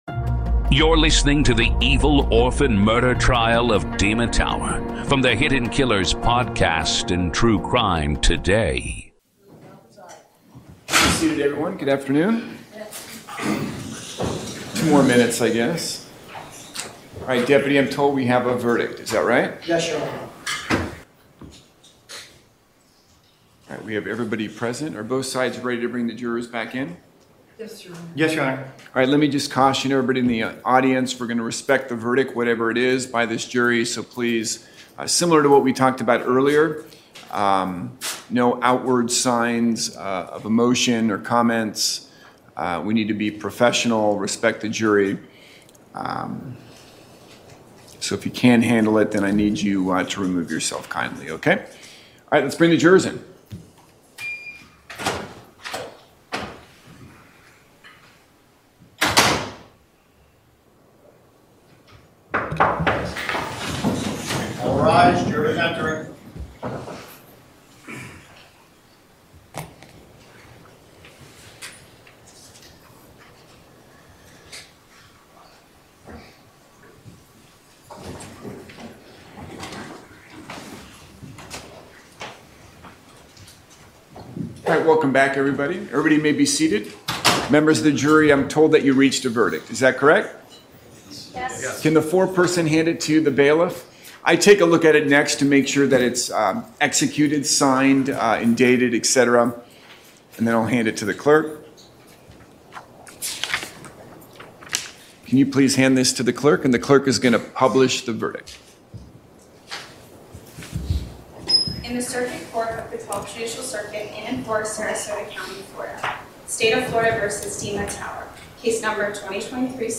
In this ongoing Hidden Killers courtroom-coverage series, we present the raw sounds of justice — no commentary, no edits — just the voices of attorneys, witnesses, and the judge as the case unfolds in real time.